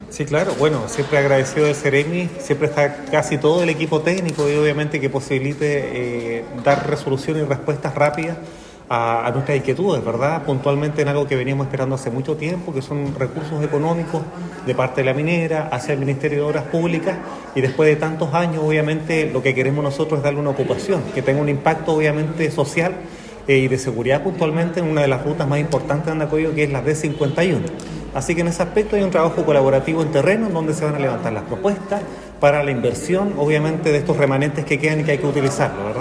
Finalmente, el alcalde de la comuna de Andacollo Gerald Cerda, indicó que